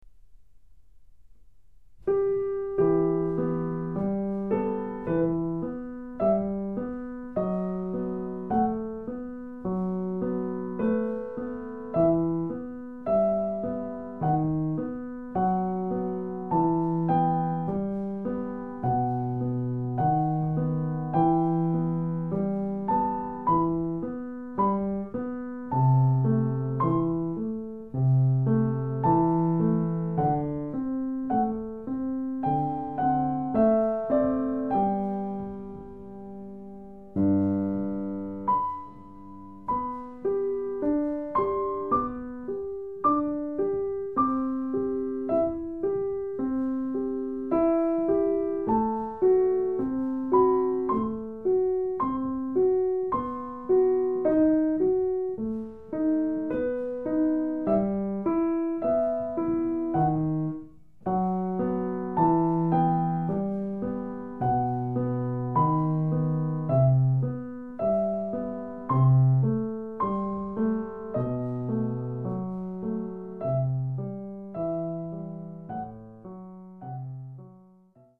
非常にゆっくりと弾かれた
2013年11月29日　サウンドアーツ（都立大）にて収録
ピアノ：Steinway&Sons D-274